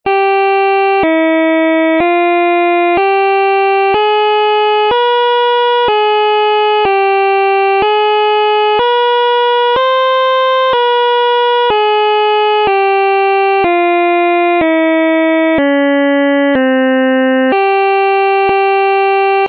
Κλίμακα
Οἱ ἤχοι ἔχουν παραχθεῖ μὲ ὑπολογιστὴ μὲ ὑπέρθεση ἀρμονικῶν.